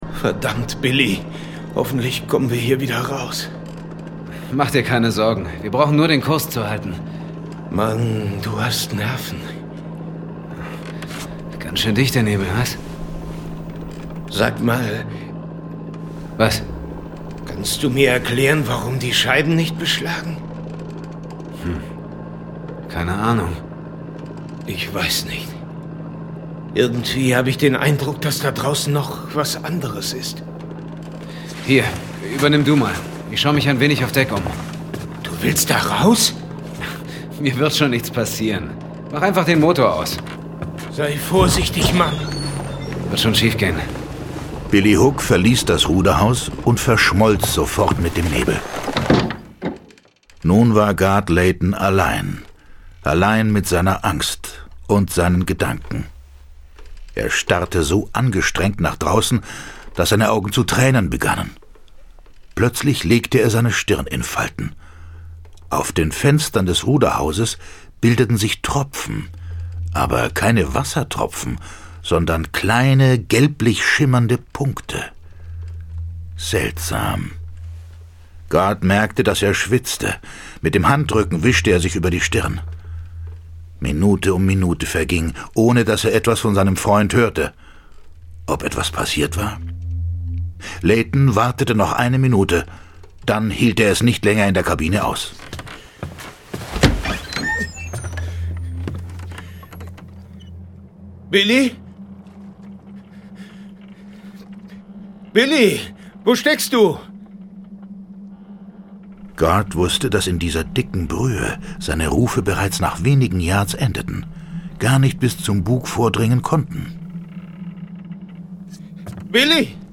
John Sinclair - Folge 36 Der Todesnebel. Hörspiel Jason Dark